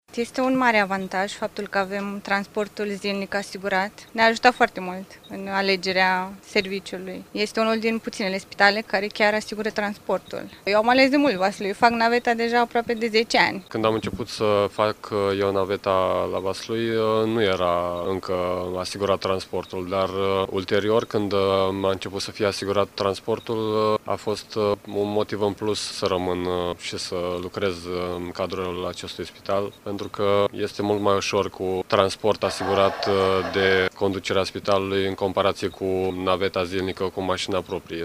Vox medici: